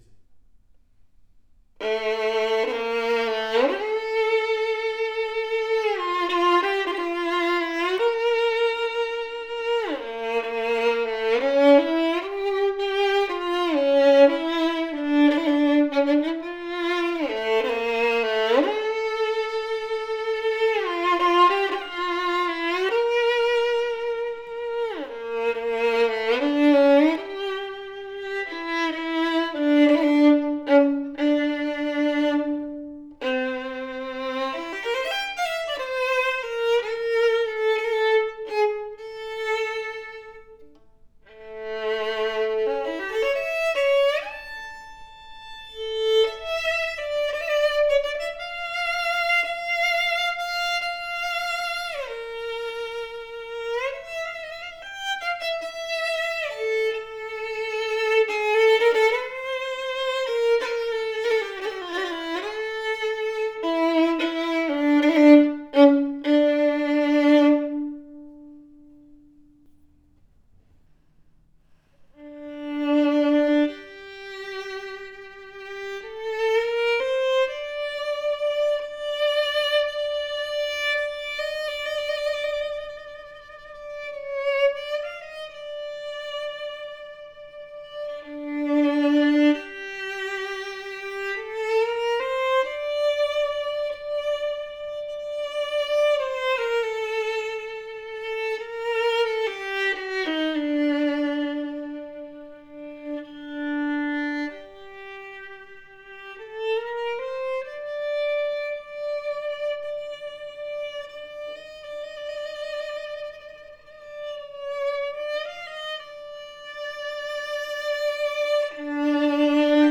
A superior ringing and projective violin with depth, robust and thick tone as audio clip represents! Deep and loud tone!
A powerful, solid violin has a thick tonal texture, yet still open and projective for easy playability.